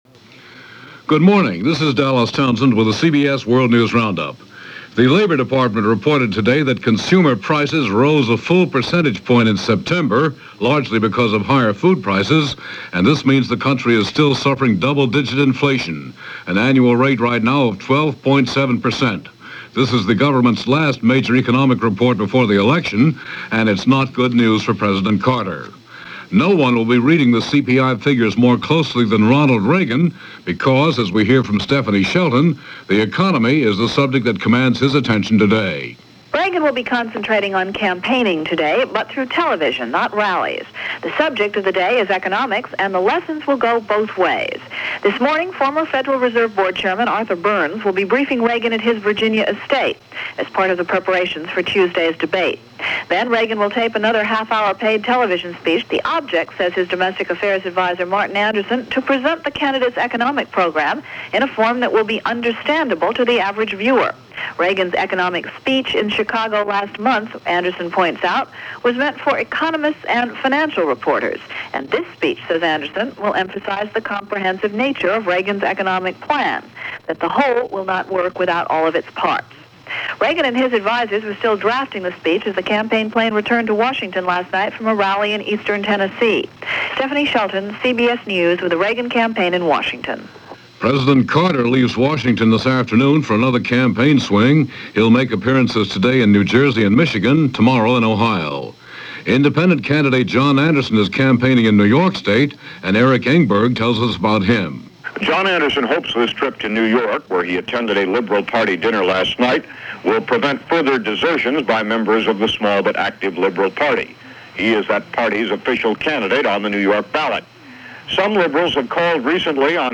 And that’s a small slice of what went on, this October 23rd, 1980 – as reported by The CBS World News Roundup.